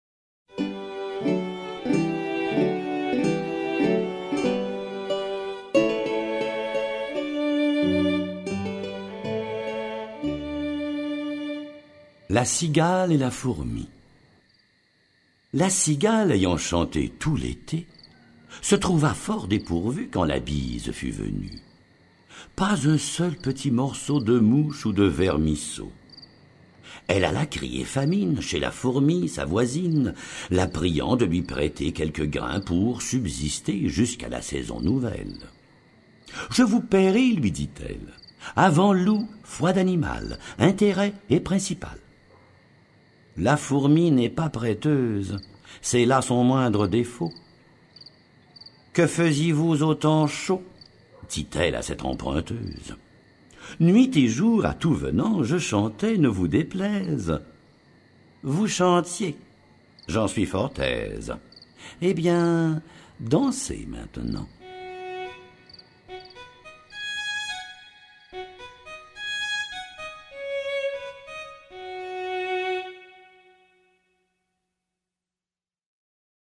Remplies d’humour et de sagesse, les fables de La Fontaine ont su traverser les barrières du temps et sont demeurées un exemple remarquable de grande littérature, ainsi qu’une source inépuisable d’inspiration et de leçon de vie. Lu par Albert Millaire Durée : 1 heure 54 min